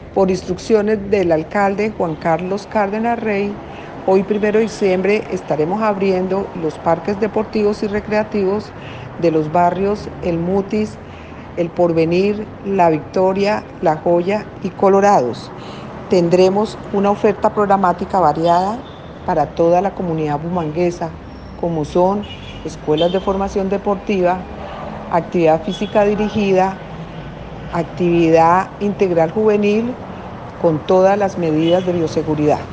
Audio Consuelo Rodríguez Gil / Directora General INDERBU.